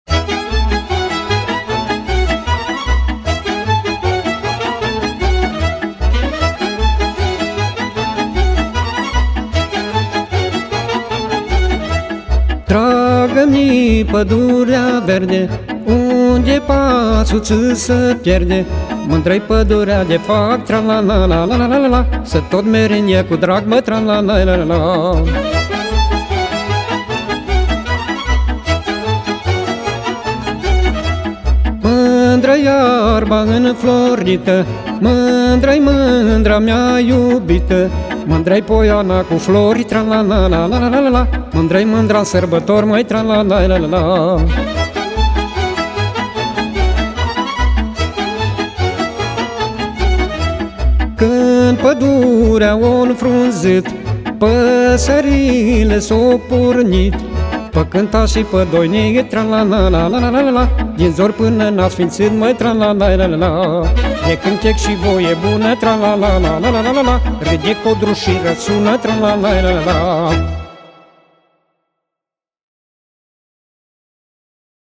Acompaniamentul cântecelor de pe acest compact disc a fost asigurat de către Orchestra profesionistă